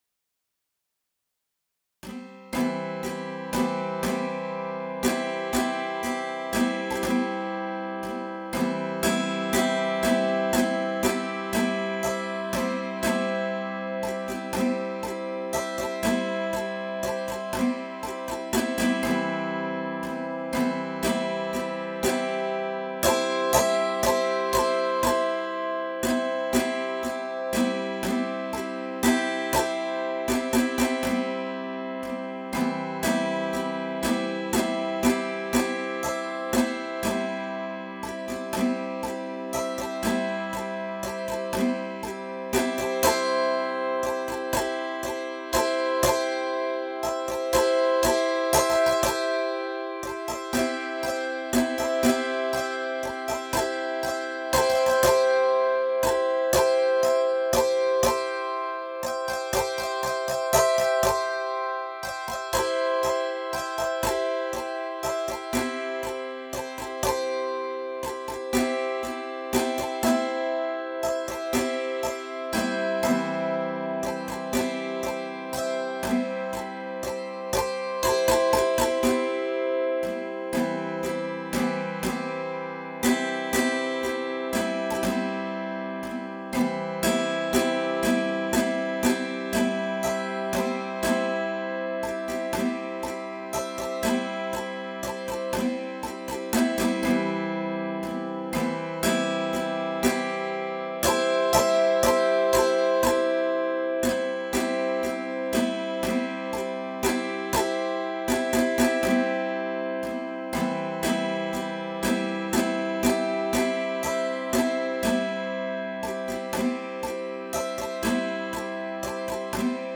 Die Autoharp im Zusammenspiel mit einem Cajon und einem Banjo.
autoharp-duett.mp3